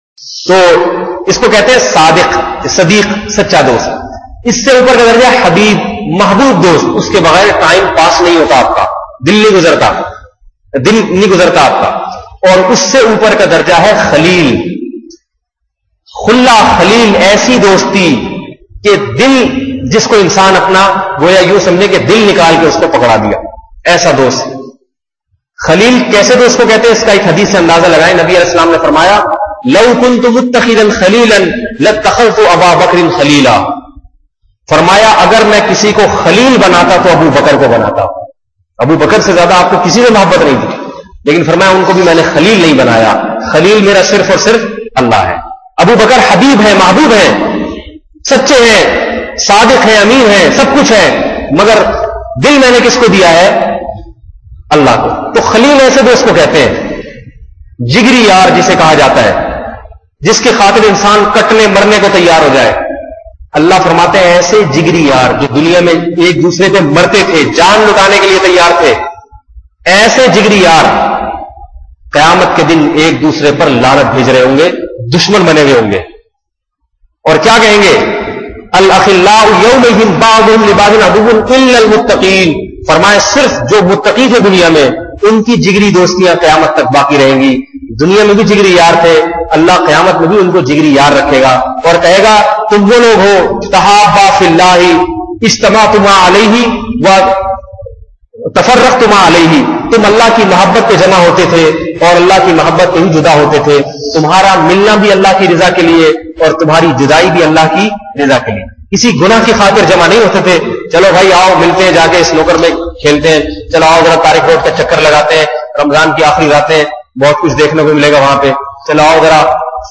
Audio Bayanat